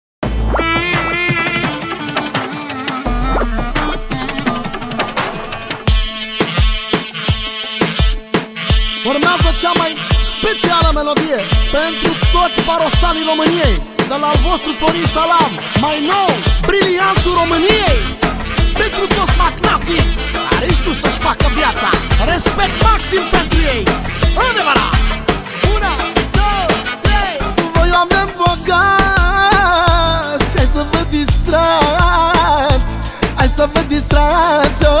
# Easy Listening